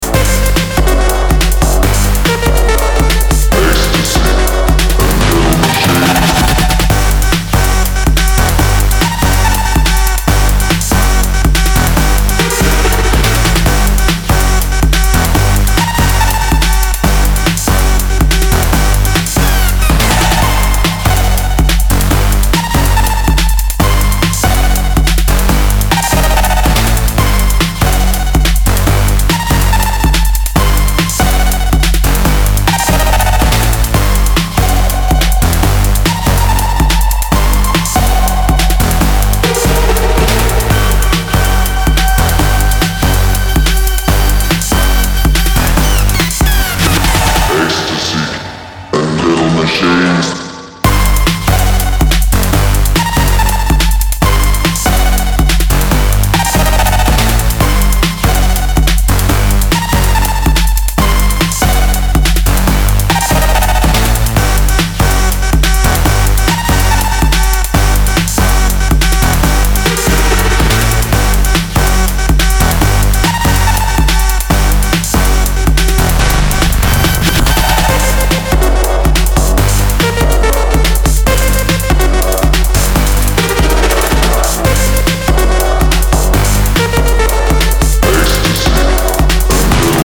dark electro funk
Electro Techno Acid